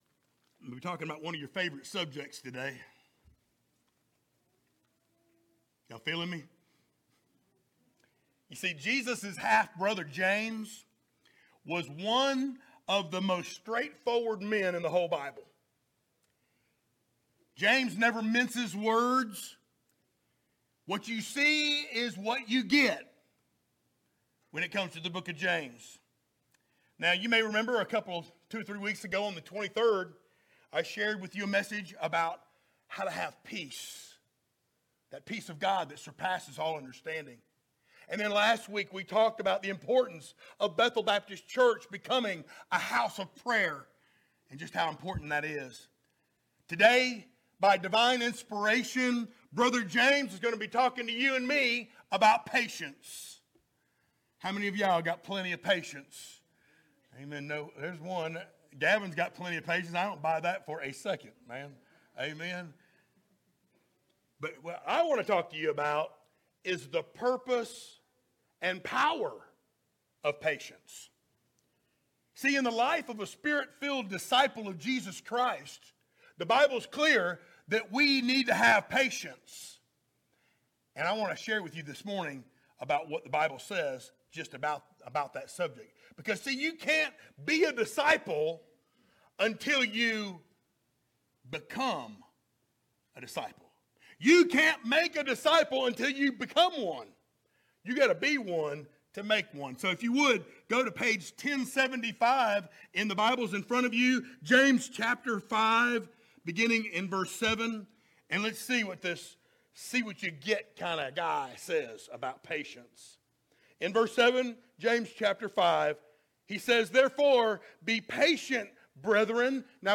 sermons Passage: James 5:7-11 Service Type: Sunday Morning Download Files Notes Topics